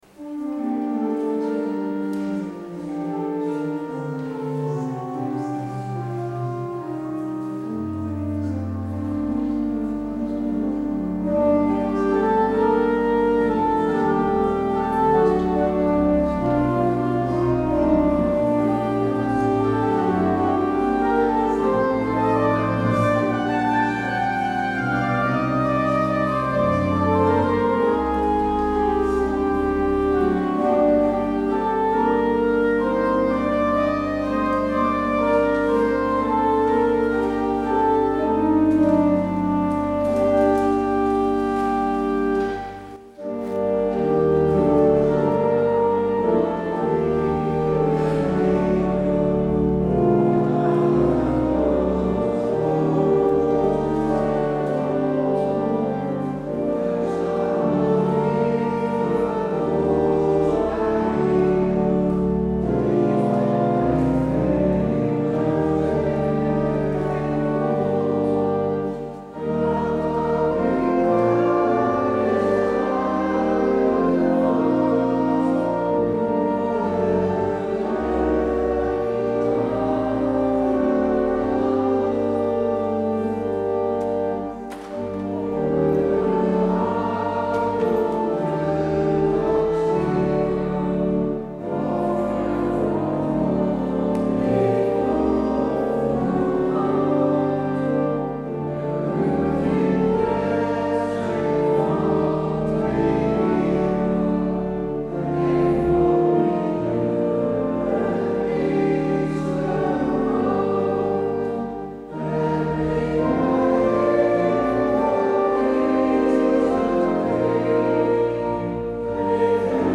 Luister deze kerkdienst terug